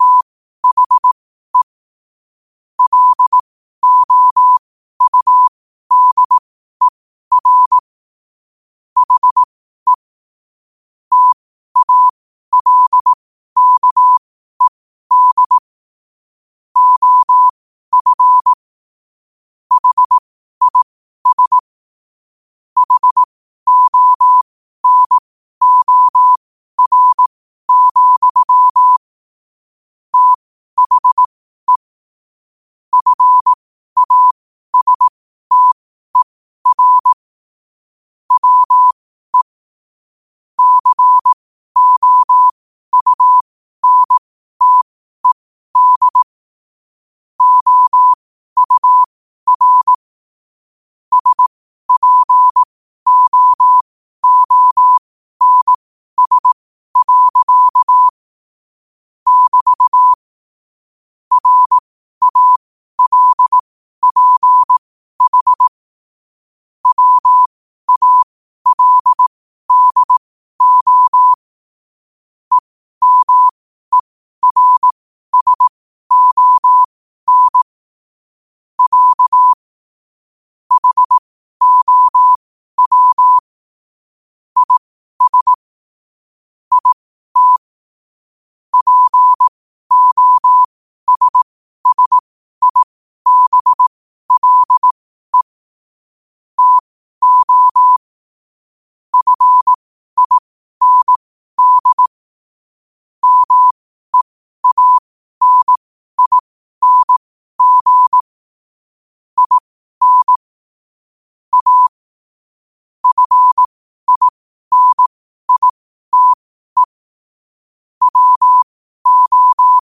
New quotes every day in morse code at 12 Words per minute.